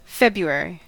Ääntäminen
Ääntäminen Tuntematon aksentti: IPA: /ˈhel.miˌkuː/ Haettu sana löytyi näillä lähdekielillä: suomi Käännös Ääninäyte Erisnimet 1.